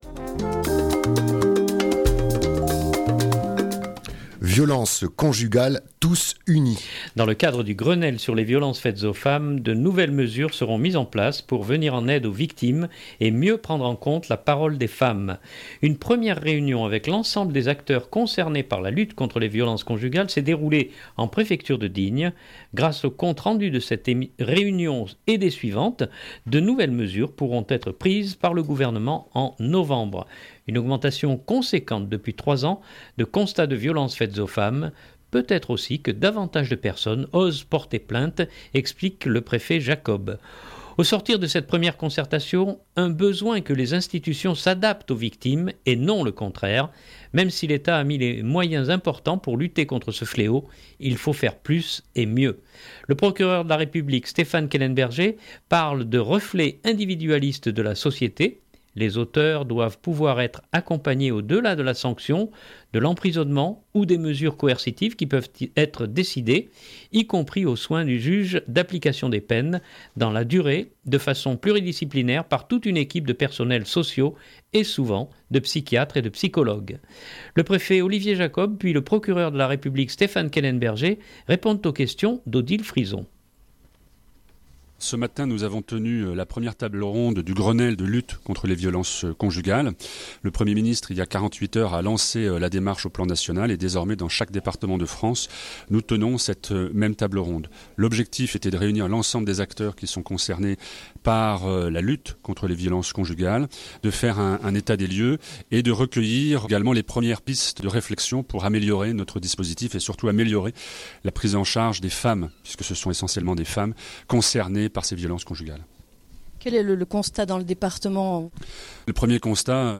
Le préfet Olivier Jacob puis le Procureur de la République Stéphane Kellenberger répondent aux questions